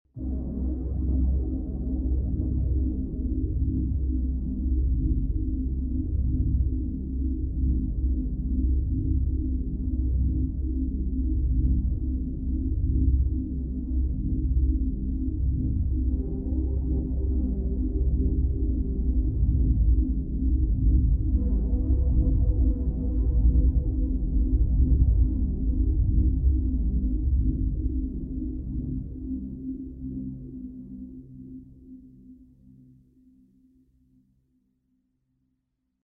Ambience2 >>
8: Lägg till bakgrundsljud (ambience) på kanalerna under.
ambience2.mp3